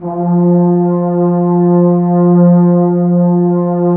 ALPHORN F-1.wav